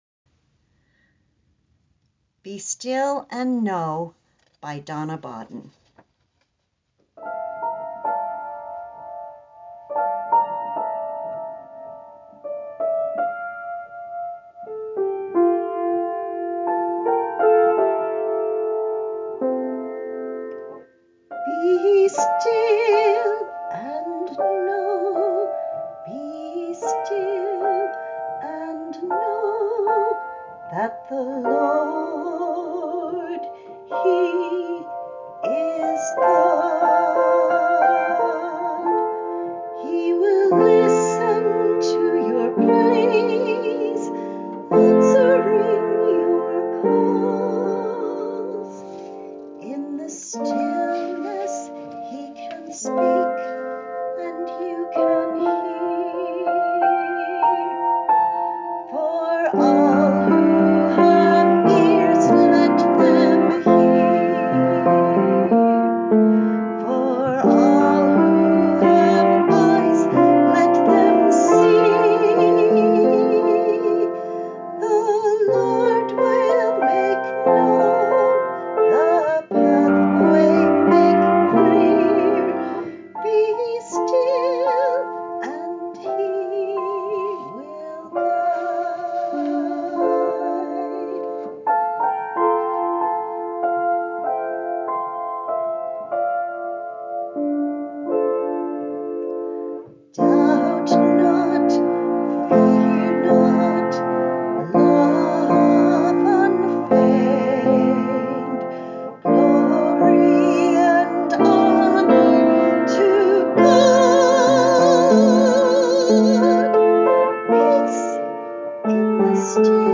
Duet, SA
Voicing/Instrumentation: SA , Duet